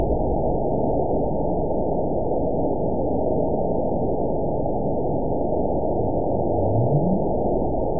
event 920435 date 03/25/24 time 00:21:14 GMT (1 year, 1 month ago) score 9.65 location TSS-AB02 detected by nrw target species NRW annotations +NRW Spectrogram: Frequency (kHz) vs. Time (s) audio not available .wav